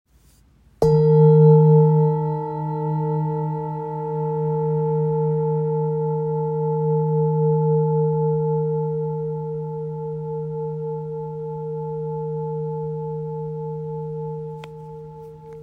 This enhanced oscillation makes it perfect for bodywork, as its deep, resonant tones penetrate the body, promoting relaxation, balancing energy centers, and restoring harmony on a cellular level.
Its rich tones and sustained vibrations make it a versatile tool for meditation, energy healing, and therapeutic use.